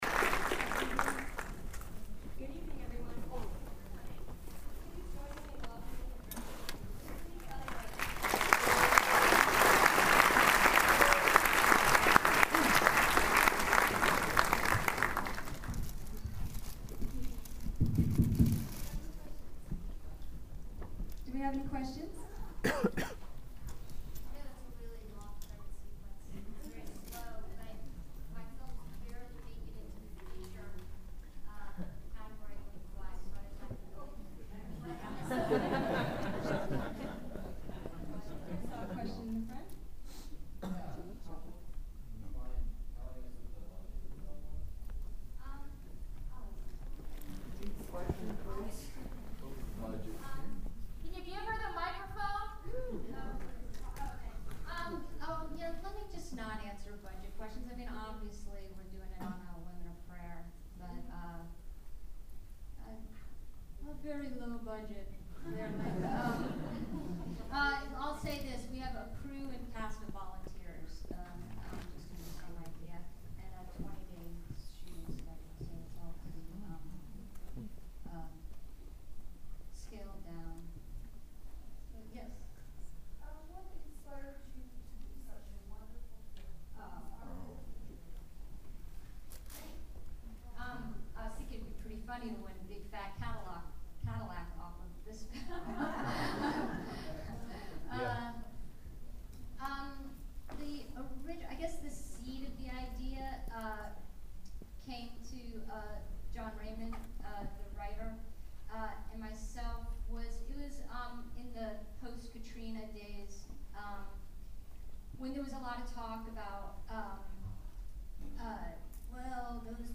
wendyandlucy_qa.mp3